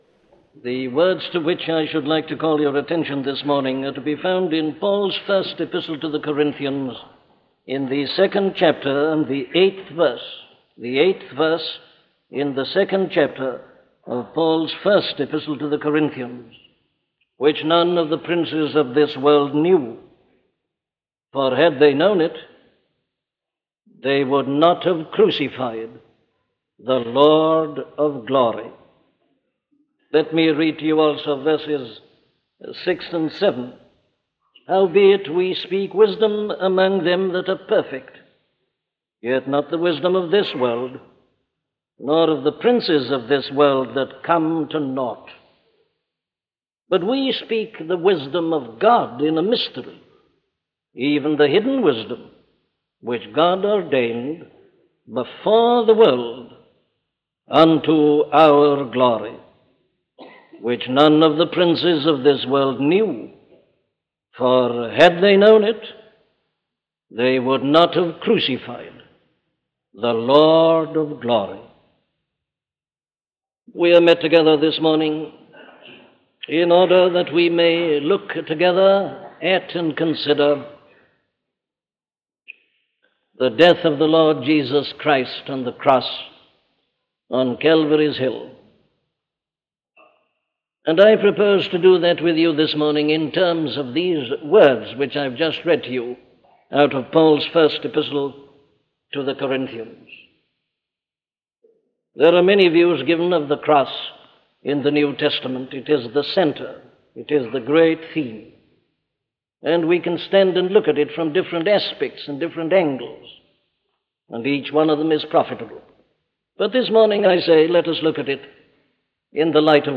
A collection of sermons on Sermons on the Cross by Dr. Martyn Lloyd-Jones
These sermons on the Cross by Dr. Martyn Lloyd-Jones were delivered at Westminster Chapel in London throughout his ministry there from 1939-1968.